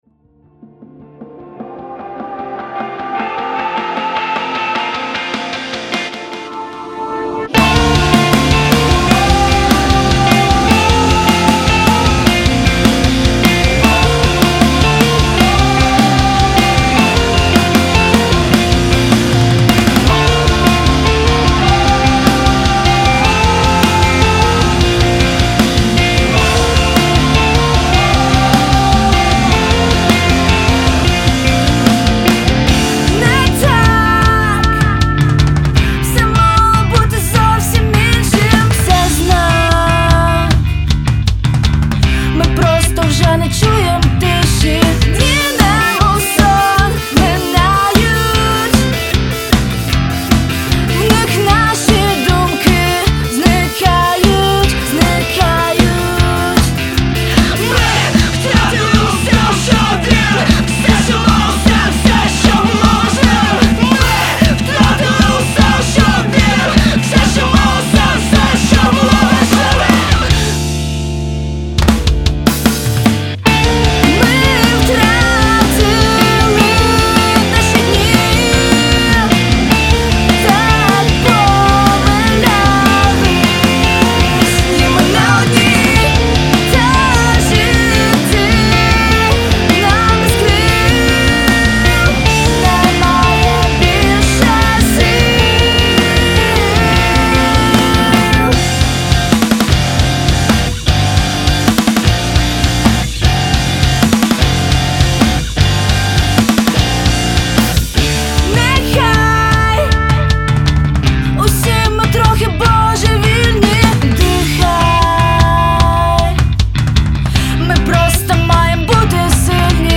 Український рок | Збірка україномовної рок-музики
• Жанр: alternative rock